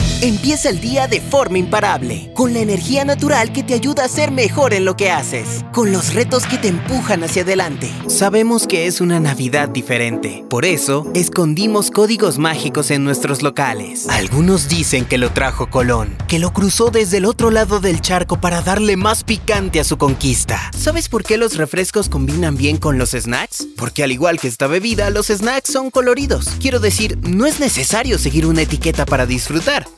VOICE OVER TALENT
Demo Comercial